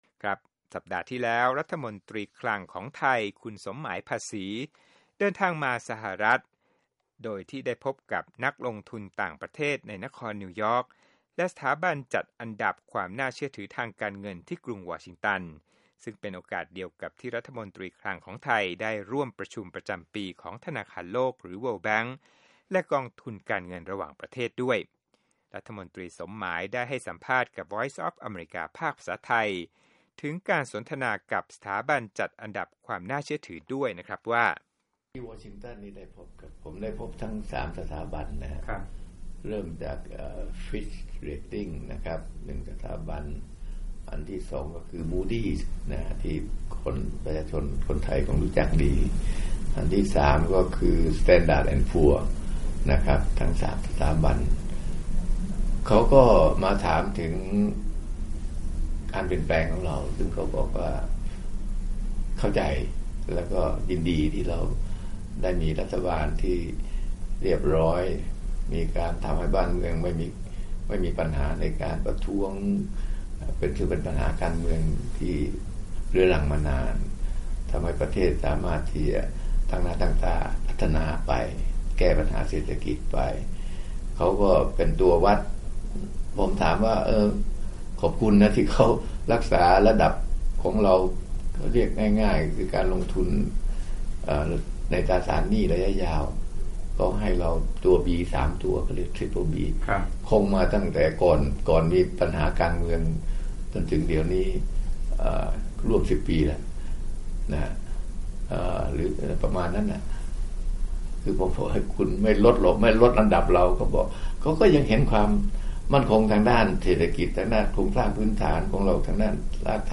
สัมภาษณ์พิเศษ: รัฐมนตรีคลังสมหมาย ภาษีพบนักลงทุนในนิวยอร์คเพื่อให้ข้อมูลเรื่องเศรษฐกิจไทยหลังปฏิวัติ